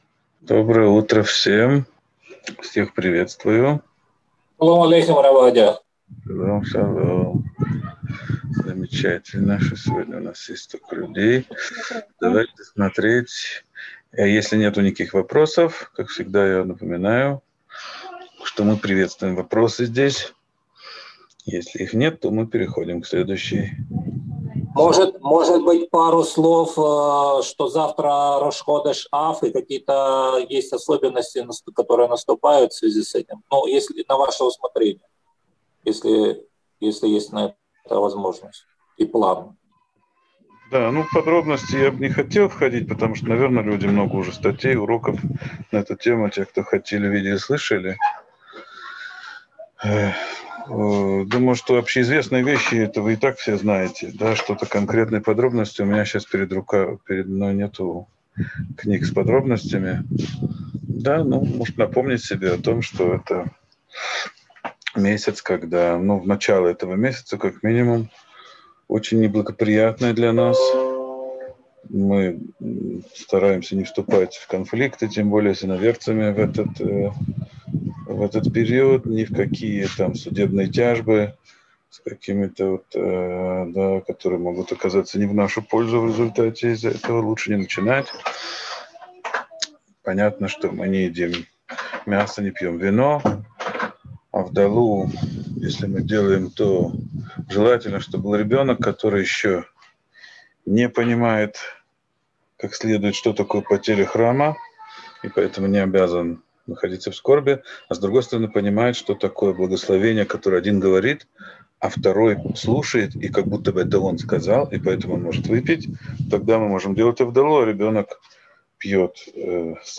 Урок 35. Заповедь 29. Не служить идолу специфическим служением